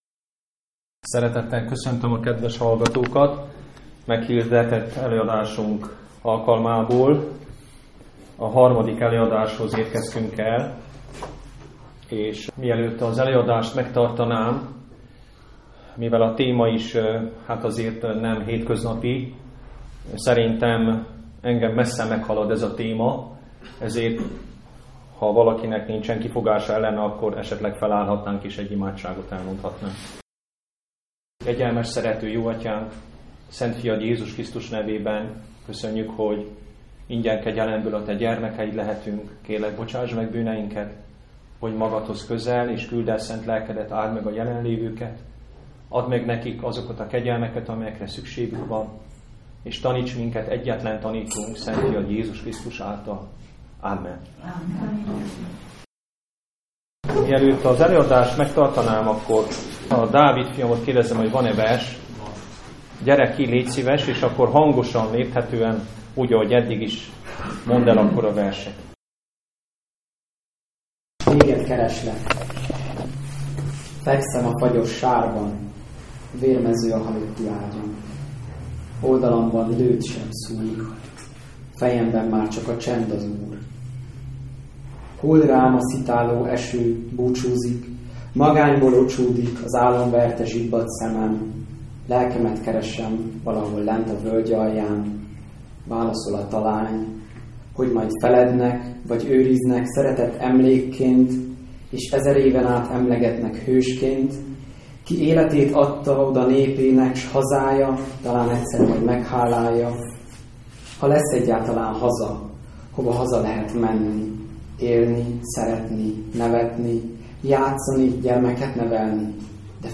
Ez a III. előadás itt hallgatható meg!